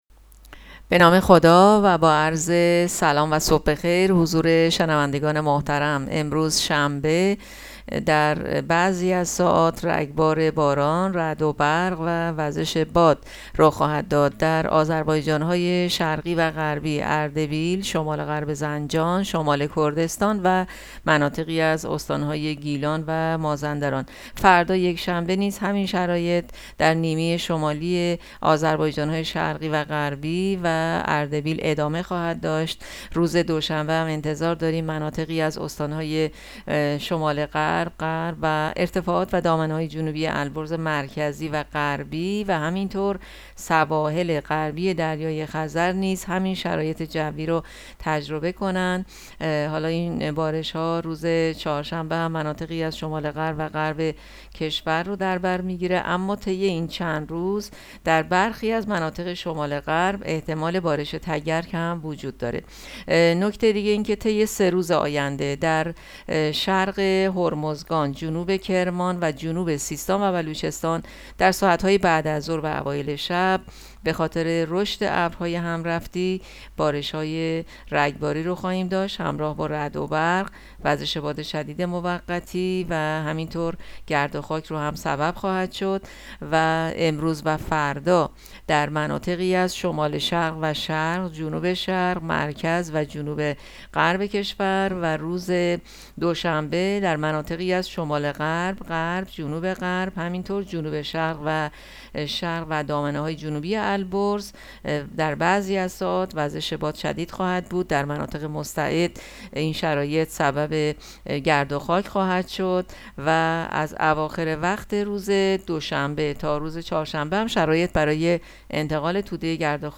گزارش رادیو اینترنتی پایگاه‌ خبری از آخرین وضعیت آب‌وهوای ۲۰ اردیبهشت؛